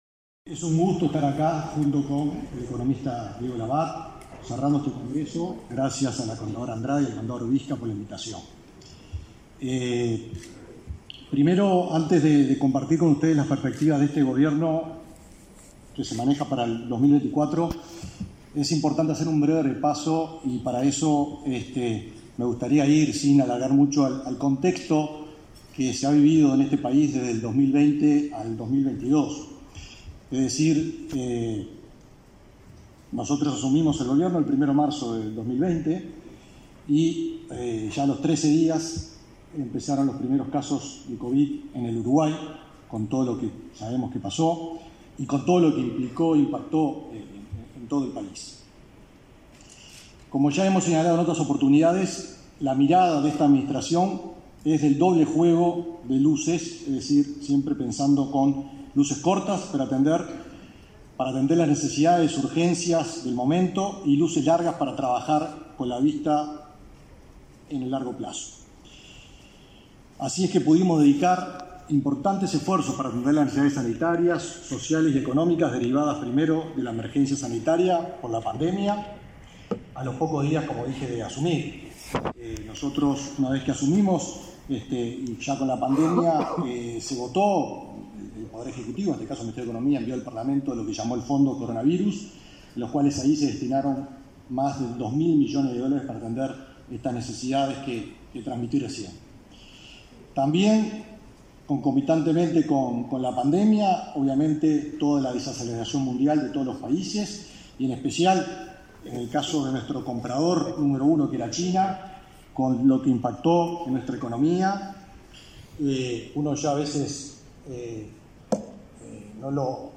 Disertación sobre las perspectivas económicas para 2024
La actividad fue organizada por el Colegio de Contadores.